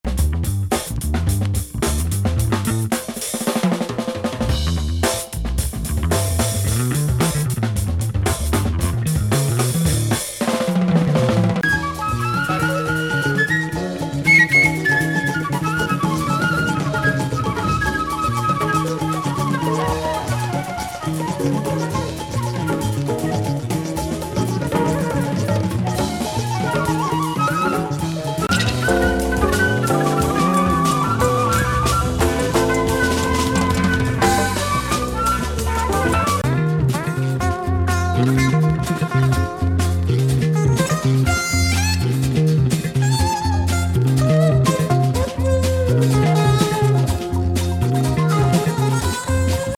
エスノ・フリー
ベースのストレンジ・ジャズ